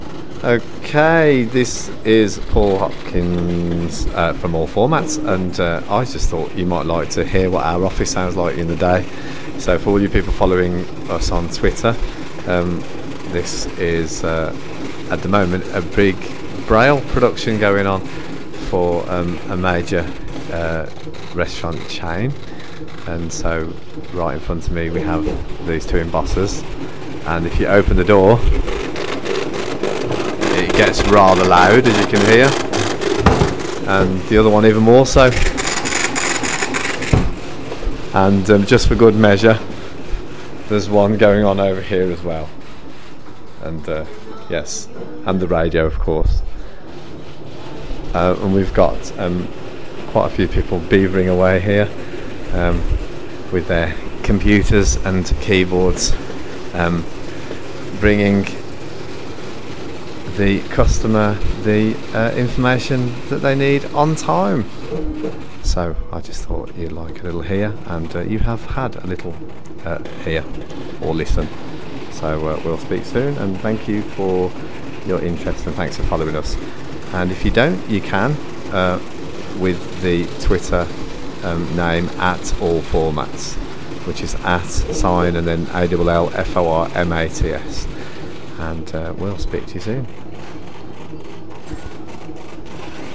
Another busy day at All Formats! All Braille Embossers are Go so, lots of interesting noise... Enjoy!